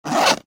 Звуки сумки, ранца
Звук расстегивания кармана рюкзака